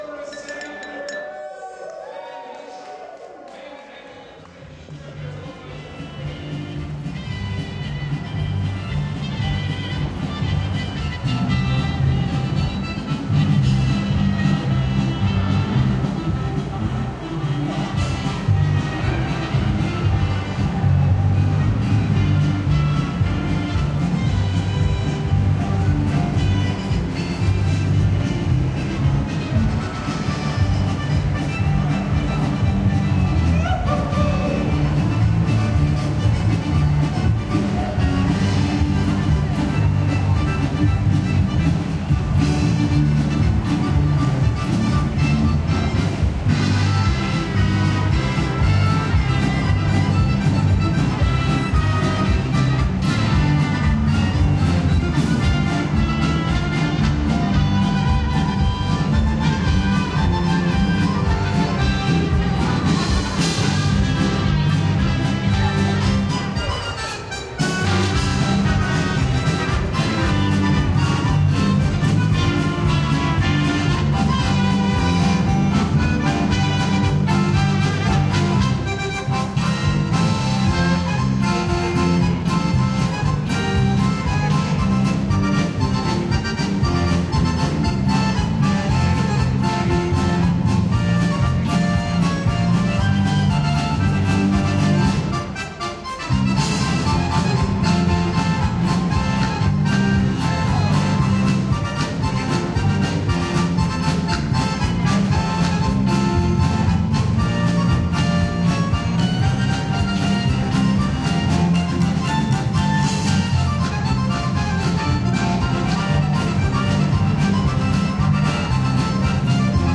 oktoberfestambience.mp3.ogg